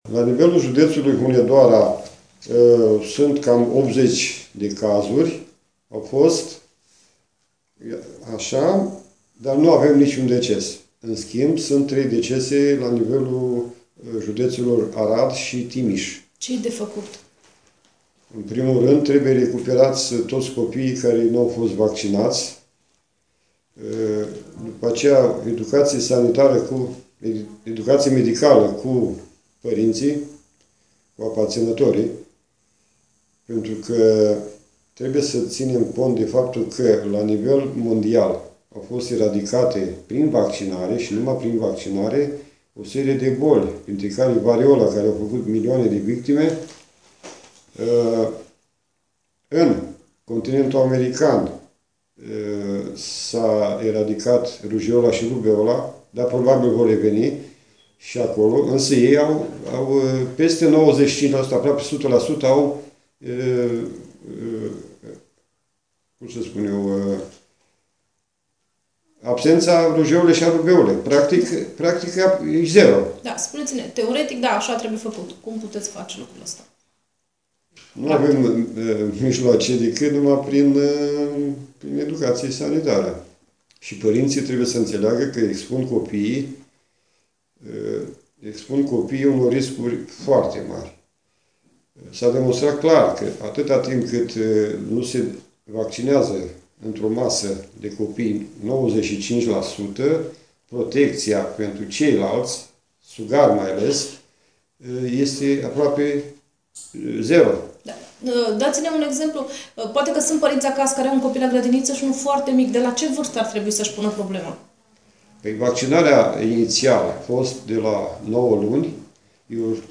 Interviu medic de familie: “Rujeola e doar inceputul”